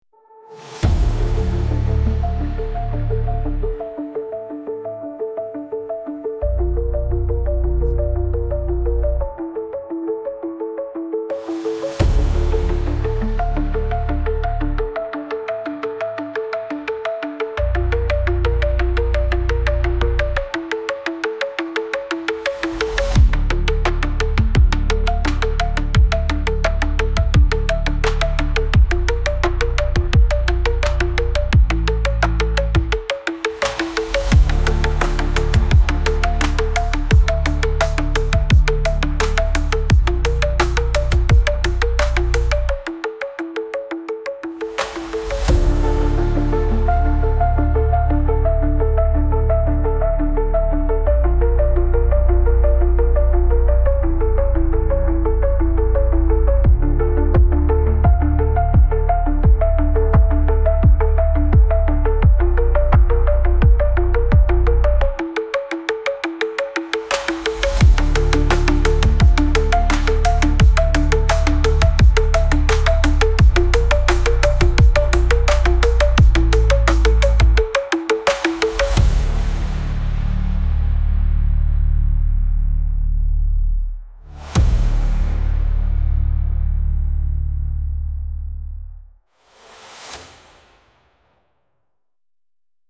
Genre: Electronic / Cinematic / Beats
Mood: Suspense / Dark / Mysterious